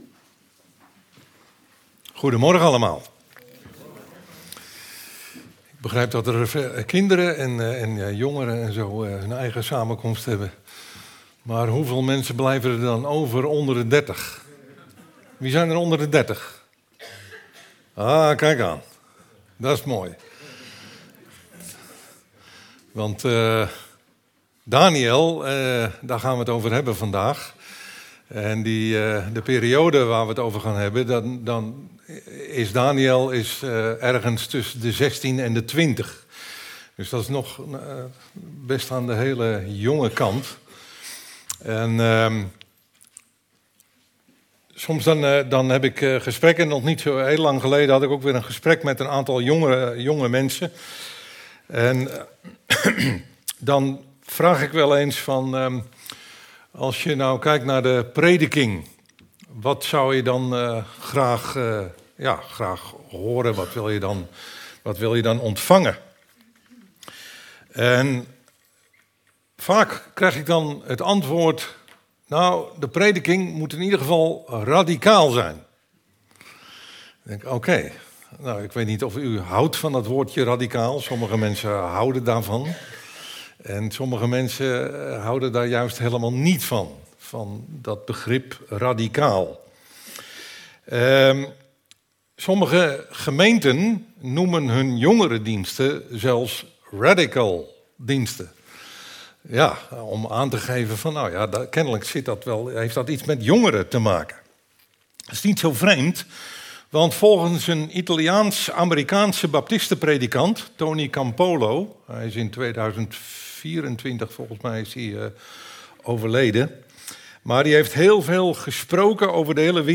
Passage: Daniel 1:3-15 Dienstsoort: Eredienst « Wie bent U